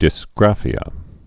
(dĭs-grăfē-ə)